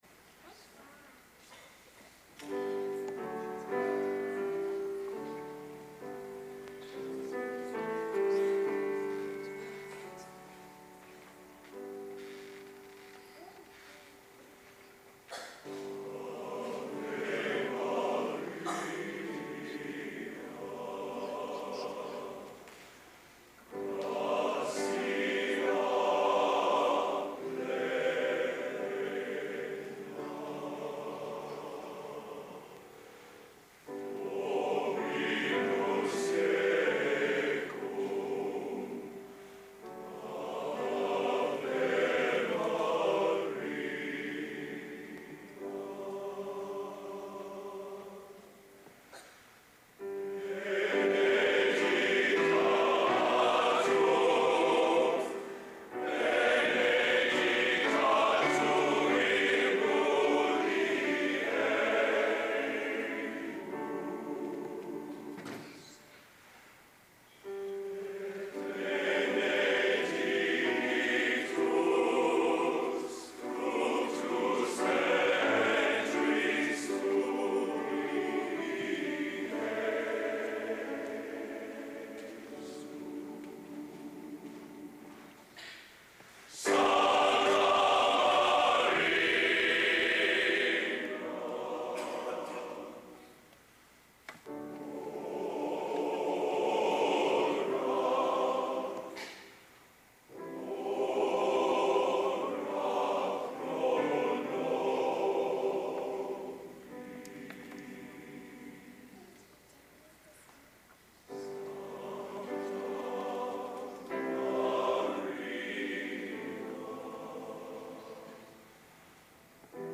Location: Purdue Memorial Union, West Lafayette, Indiana
Genre: Classical | Type: